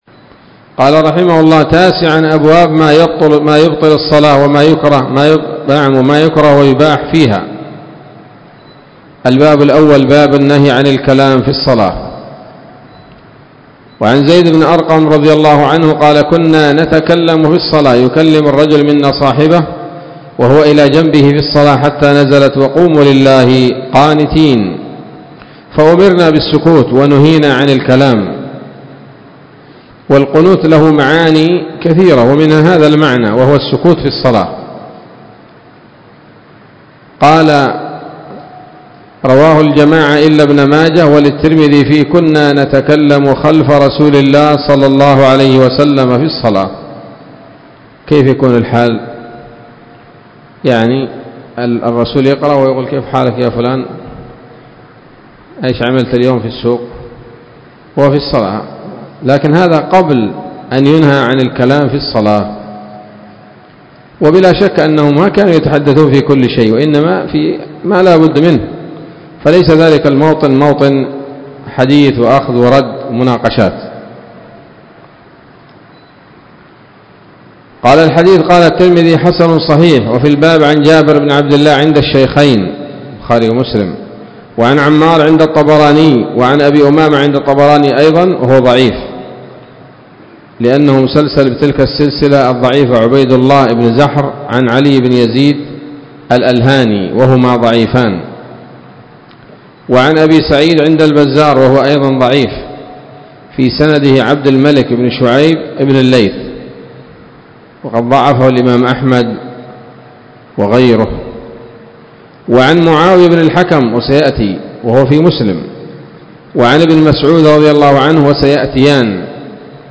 الدرس الأول من أبواب ما يبطل الصلاة وما يكره ويباح فيها من نيل الأوطار